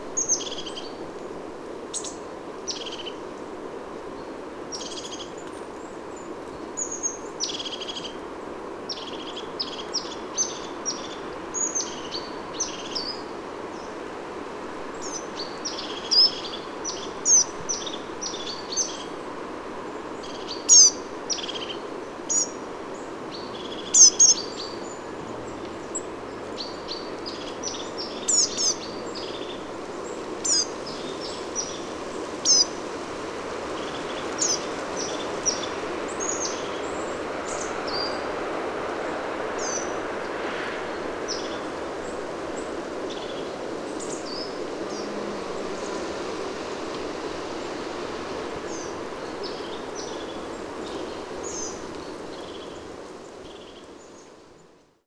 101109, European Crested Tit Lophophanes cristatus, Coal Tit Periparus ater, calls whilst wandering through tree tops,
Altenfeld, Germany